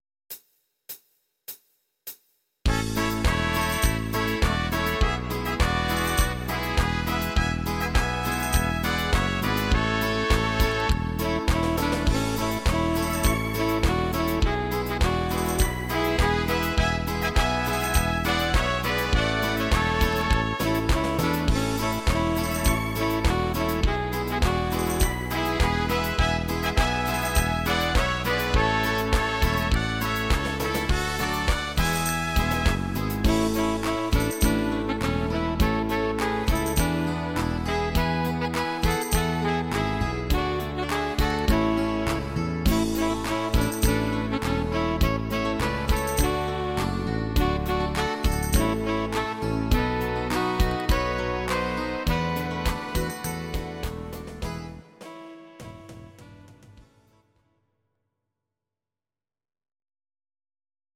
Audio Recordings based on Midi-files
German, Duets, Traditional/Folk, Volkstï¿½mlich